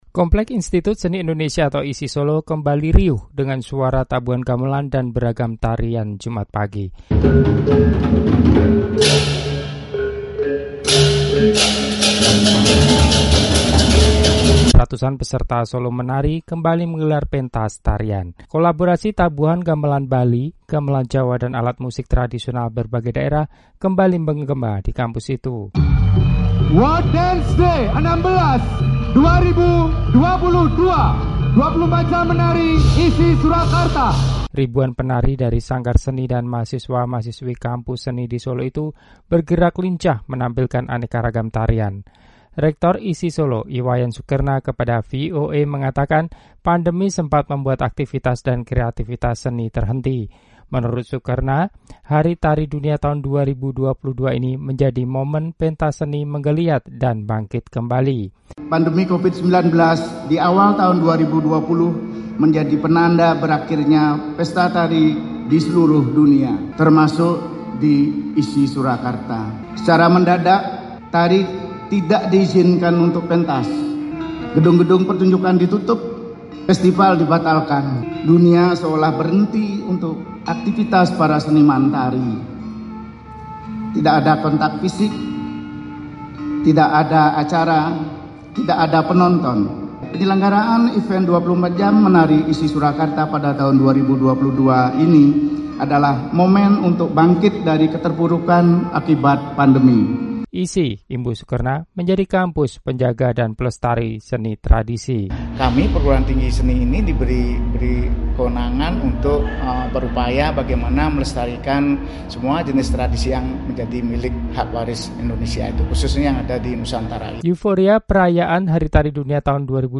Kompleks Institut Seni Indonesia ISI Solo hari Jumat (29/4) kembali riuh rendah dengan suara tabuhan gamelan dan beragam tarian ketika ratusan peserta “Solo Menari” kembali menggelar pentas tarian.
Kolaborasi tabuhan gamelan Bali, gamelan Jawa, dan alat musik tradisional berbagai daerah kembali bergema di kampus itu ketika ribuan penari yang kebanyakan adalah mahasiswa-mahasiswi beragam kampus seni di Solo bergerak lincah menampilkan aneka ragam tarian.